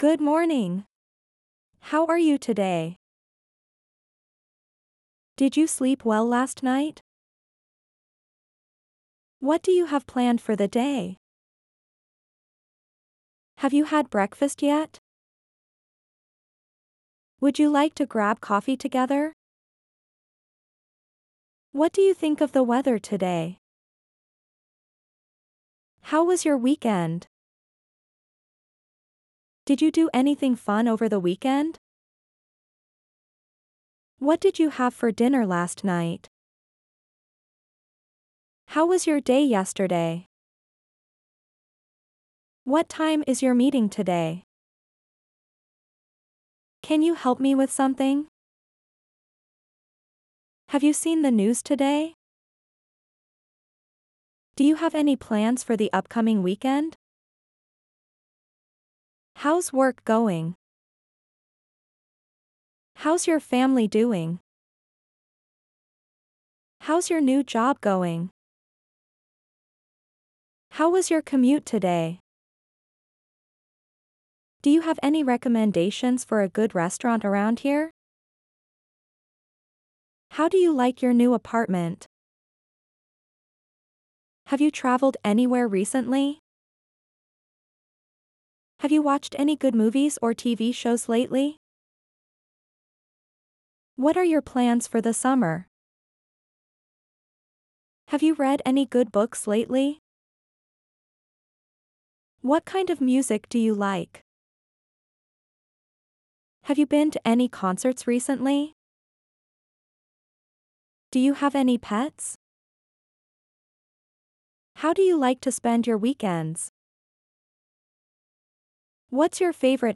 Conversación Básica 1